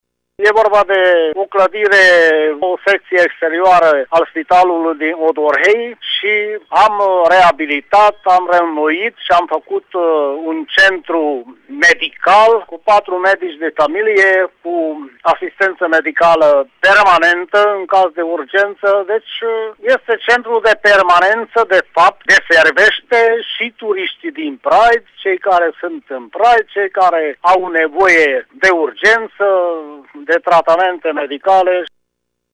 Pe lângă tratarea urgenţelor, în noua secție vor avea cabinete de consultaţii şi patru medici de familie, ne-a declarat primarul din Praid, Alexandru Bokor: